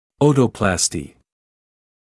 [‘əutəˌplɑːstɪ][‘оутэˌплаːсти]отопластика (пластика ушной раковины)